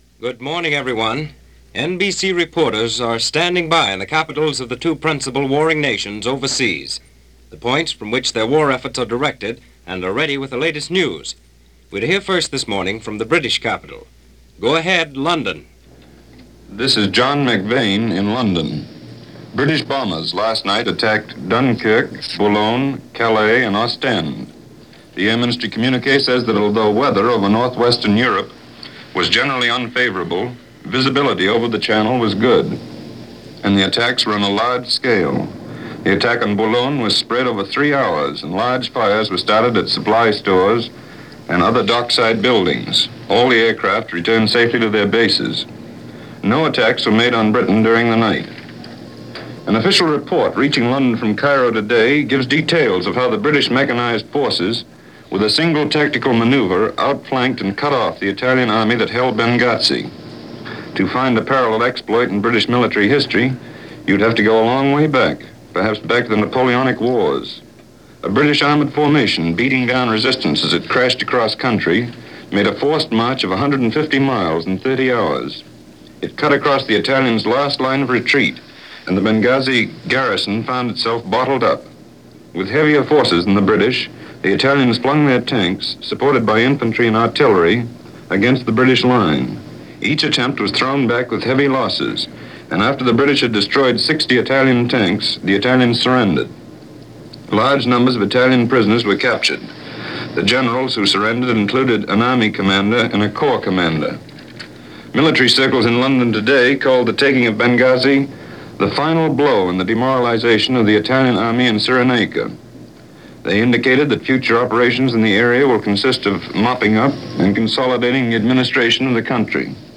Raids Over Dunkerque And Boulogne - Details On Benghazi - America As Influencer - February 8, 1941 - News from NBC News Of The World.